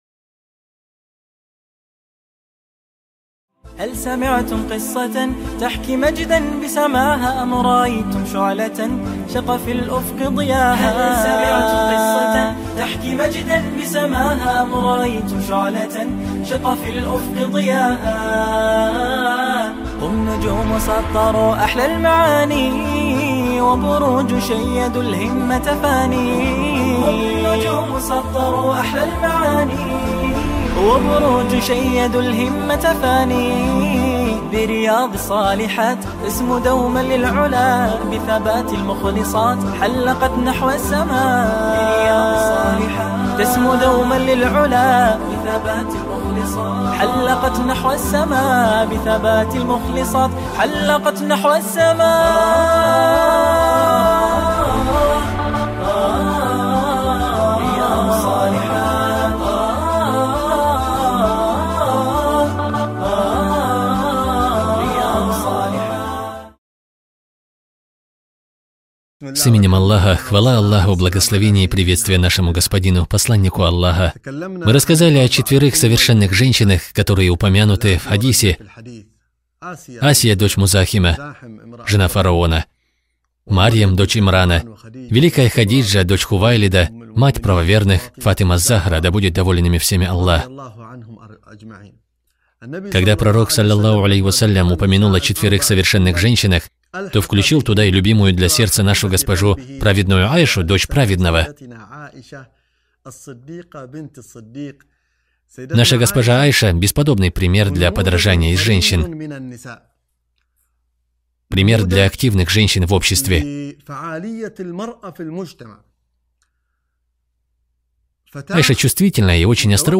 Совершенные женщины | Цикл уроков для мусульманок